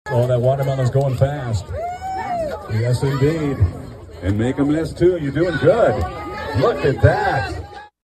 2388-watermelon-eating.mp3